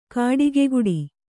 ♪ kāḍigeguḍi